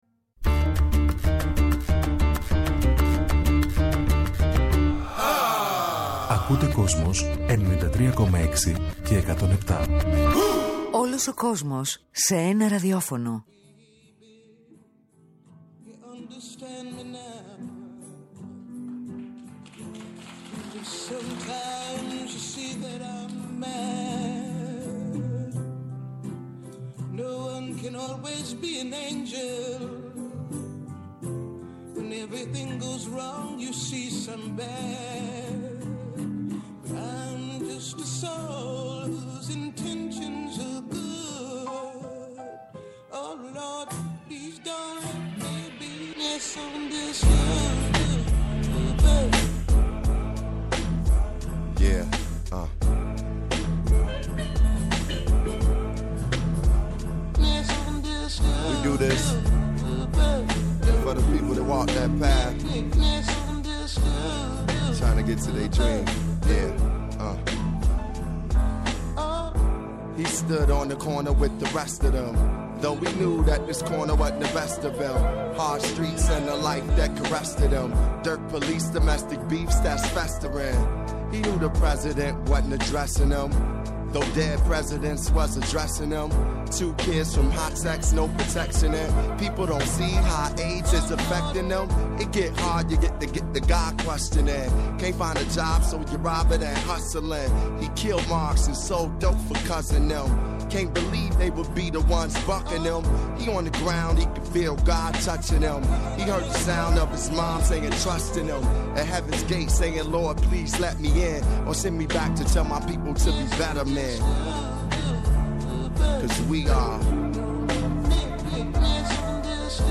Ακούστε τη Lisa Celeste Simone να μιλάει για το θρύλο της μητέρας της, τη σχέση που είχαν, τον ακτιβισμό και τη μουσική, το θέατρο Broadway και το υποψήφιο με Oscar ντοκιμαντέρ – ταινία που έκανε τη συμπαραγωγή το 2016 με άγνωστες πτυχές της ζωής της Nina Simone που ήρθαν για πρώτη φορά στο φως της δημοσιότητας.